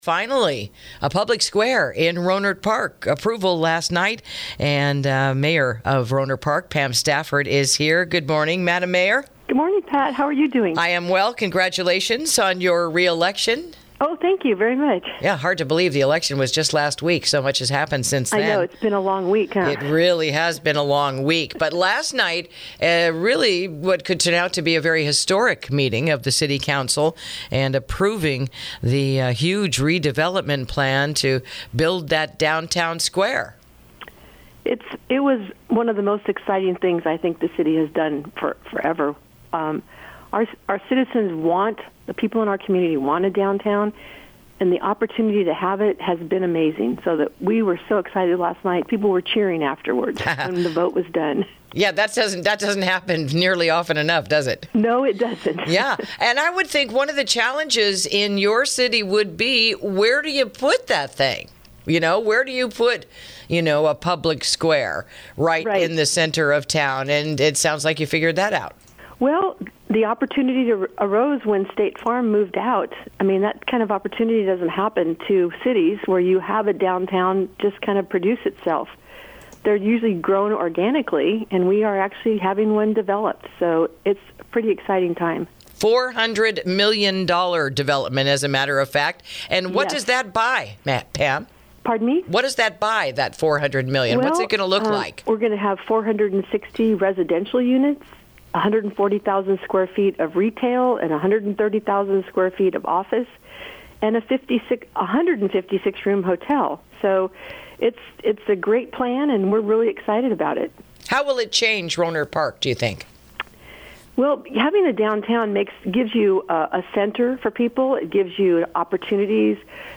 INTERVIEW: Rohnert Park’s “Station Avenue” Downtown Development Plan Going Forward